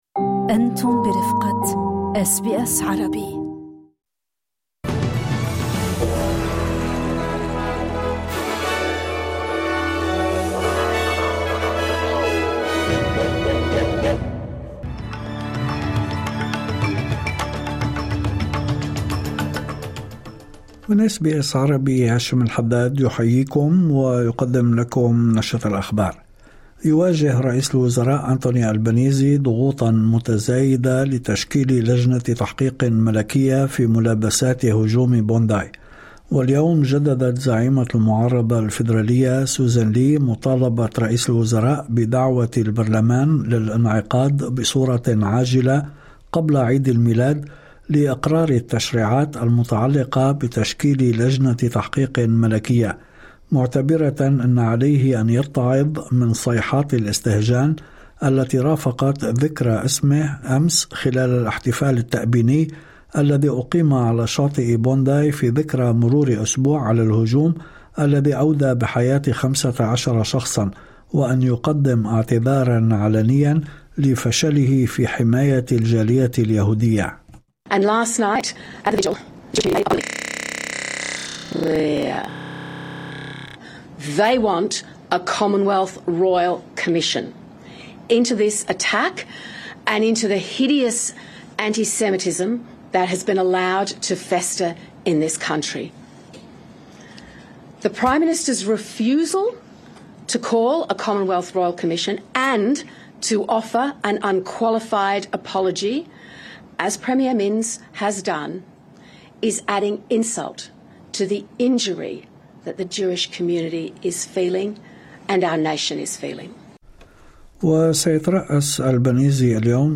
نشرة أخبار الظهيرة 22/12/2025